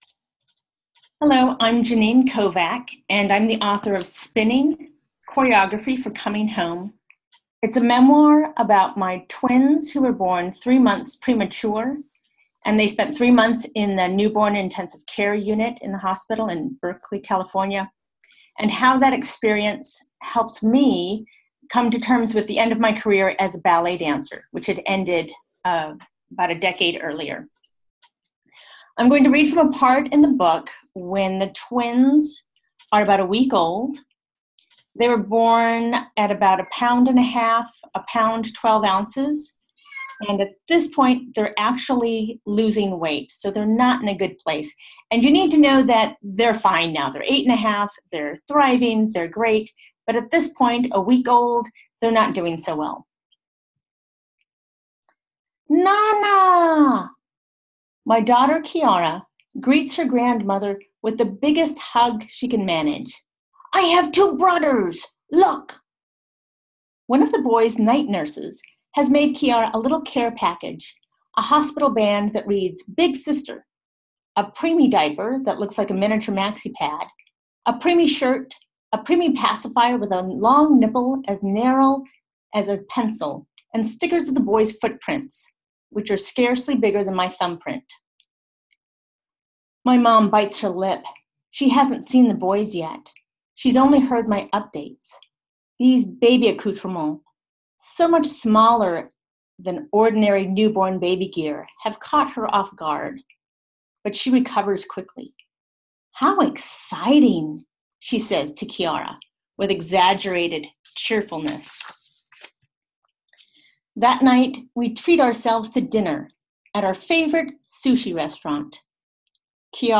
An excerpt reading